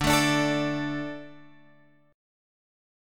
D 5th